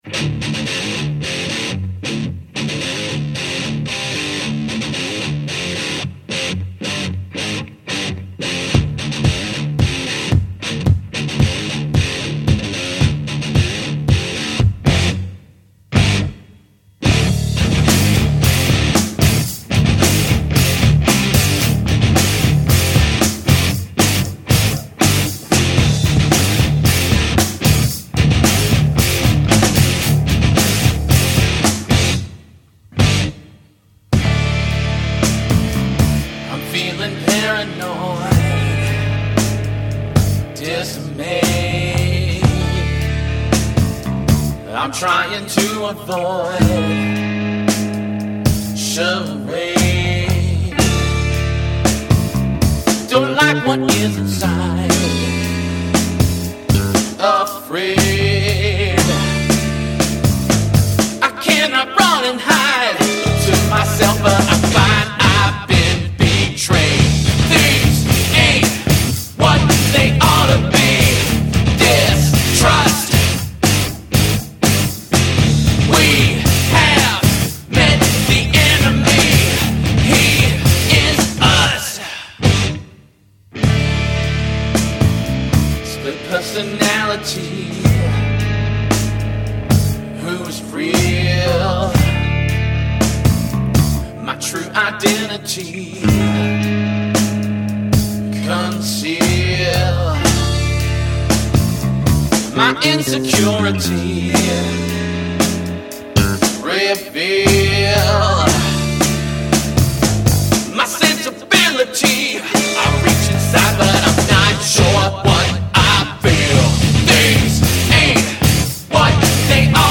Georgia as an ‘alterna-gressive’ rock group.
drums, vocals
guitar
bass